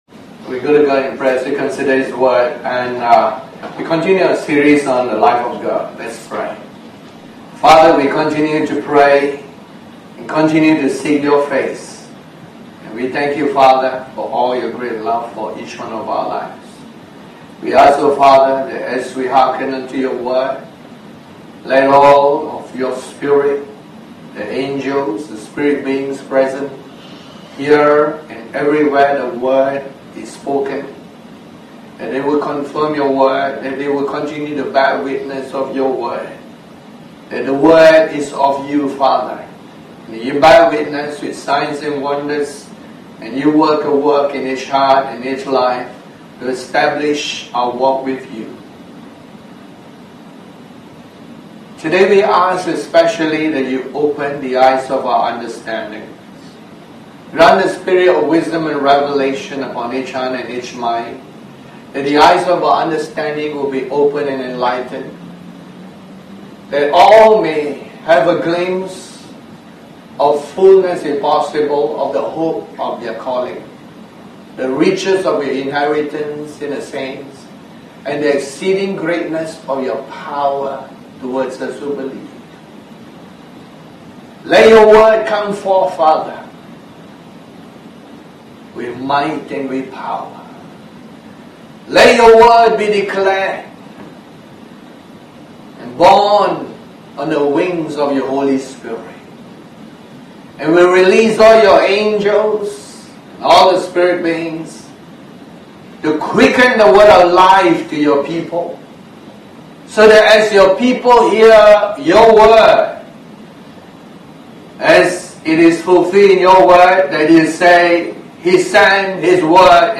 Series: The Life of God Tagged with Sunday Service